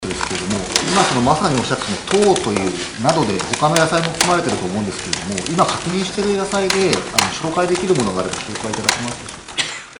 2011年3月30日、枝野幸男元官房長官は、総理が放射能に汚染された野菜の出荷・摂取制限を 福島県や茨城県に対して行ったことを記者会見で説明した。